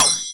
battle_item_Shield_Attack.wav